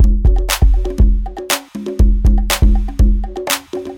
UMD Drums Loop.wav